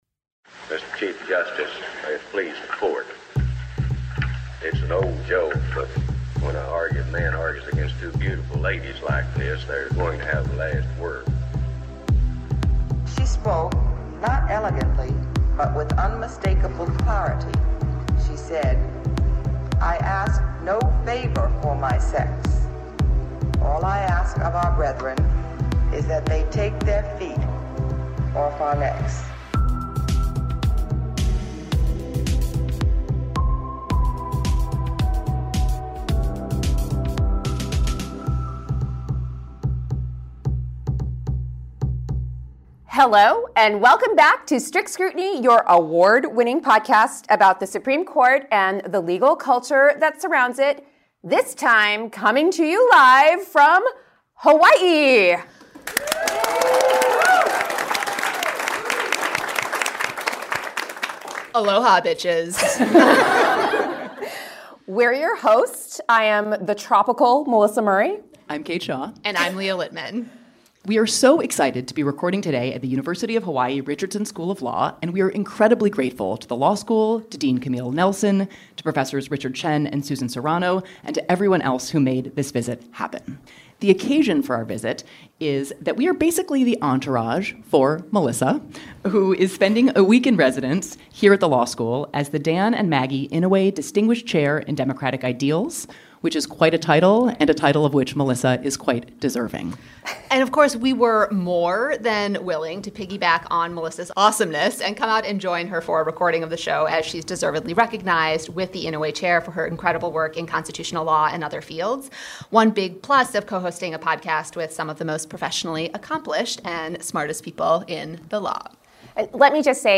Strict Scrutiny takes Hawaii!